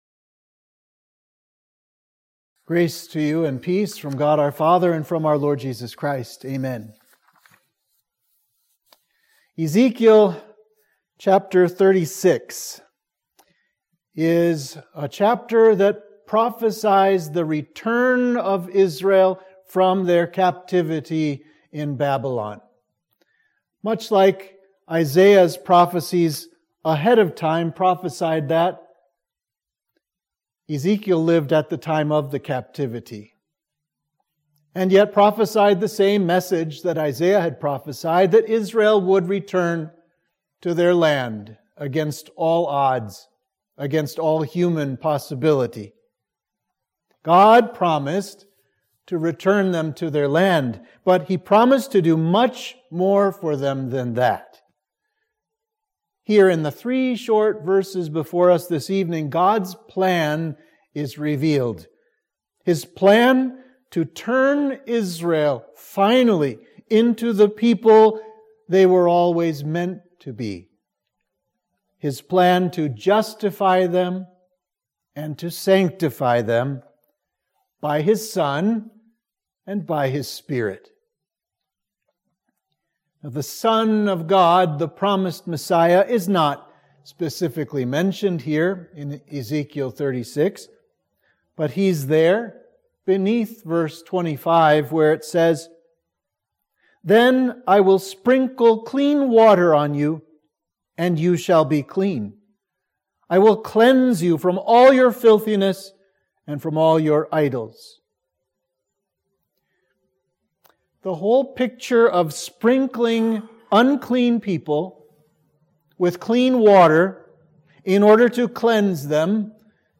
Sermon for Midweek of Exaudi